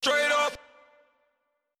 TS - CHANT (3).wav